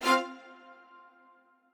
strings5_33.ogg